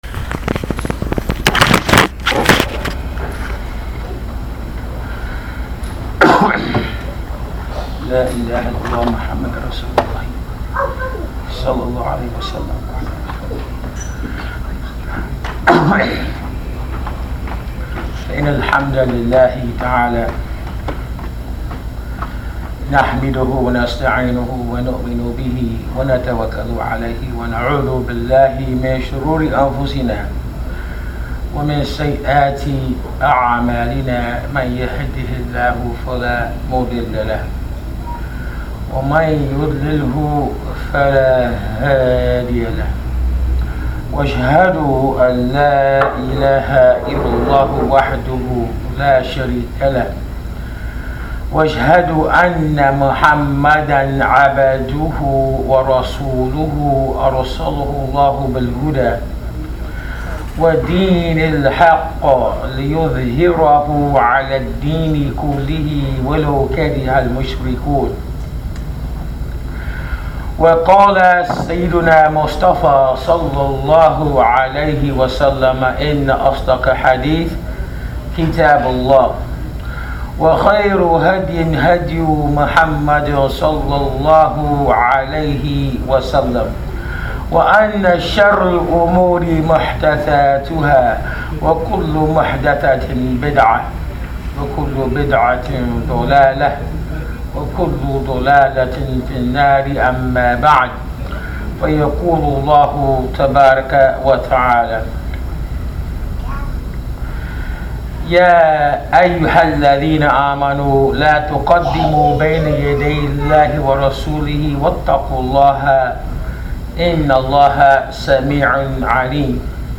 This is the topic of this khutbatul Jum’ah recorded at Masjid Ibrahim Islamic Center in Sacramento California.